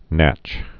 (năch)